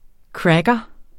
Udtale [ ˈkɹagʌ ]